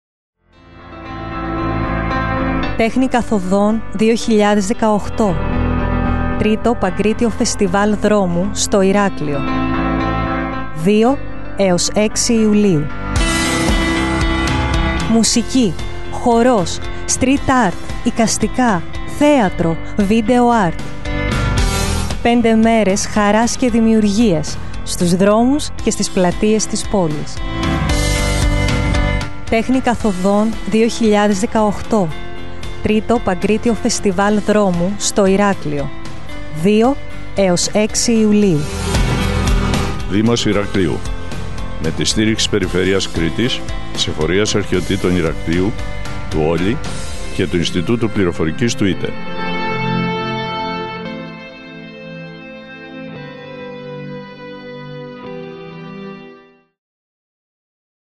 Το Ραδιοφωνικό spot (680.41 KB)
radiofoniko_spot_-_tehni_kathodon_2018.mp3